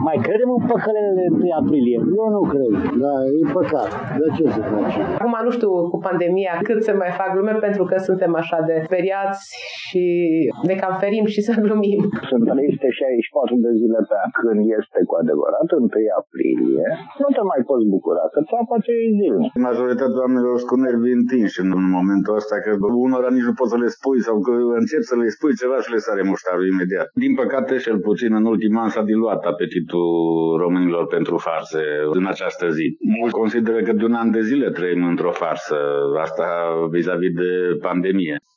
Puțin târgumureșeni sunt dispuși să mai accepte să li se facă vreo farsă de 1 aprilie, presiunea crizei sanitare pandemice punânduși serios amprenta asupra atitudinii lor: